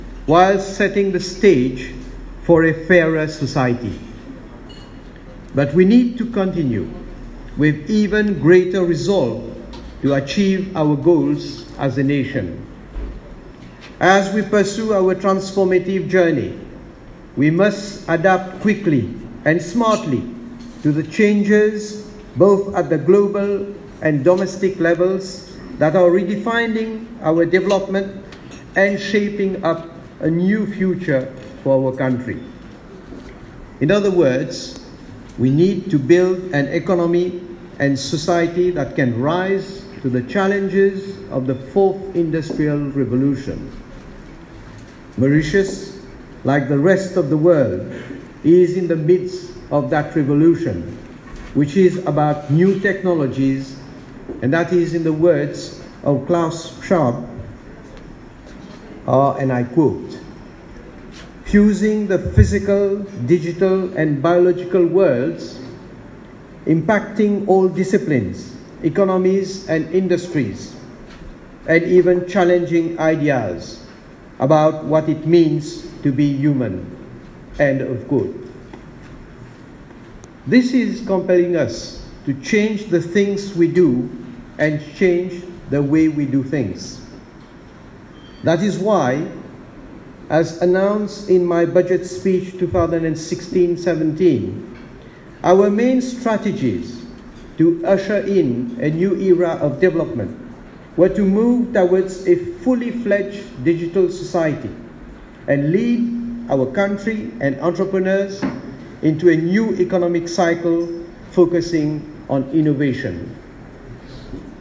Pravind Jugnauth participait, ce mardi 28 août, à un diner organisé pour le 20ème anniversaire de la Chinese Business Chamber au Domaine Anna, Flic-en-Flac. Il a mis l’accent sur le besoin de s’adapter aux changements qui détermineront l’avenir du pays sur le plan économique.